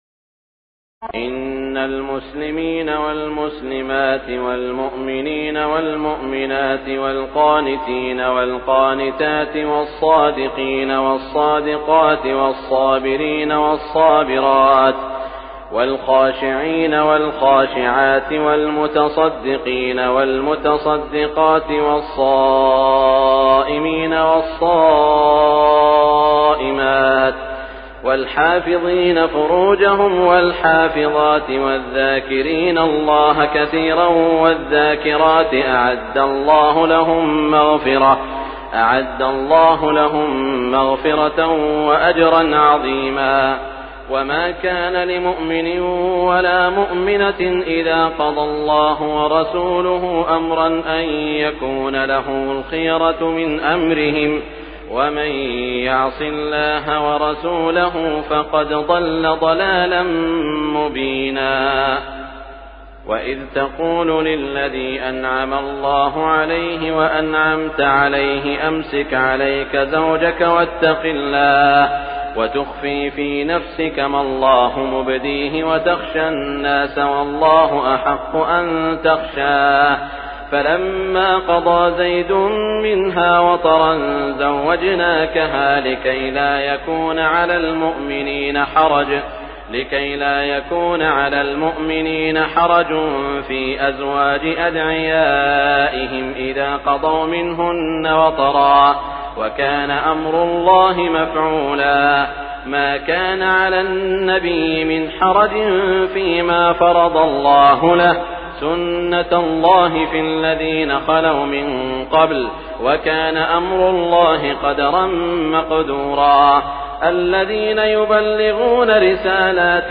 تراويح ليلة 21 رمضان 1418هـ من سور الأحزاب (35-73) وسبأ (1-23) Taraweeh 21 st night Ramadan 1418H from Surah Al-Ahzaab and Saba > تراويح الحرم المكي عام 1418 🕋 > التراويح - تلاوات الحرمين